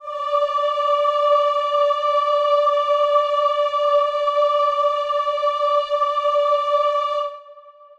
Choir Piano
D5.wav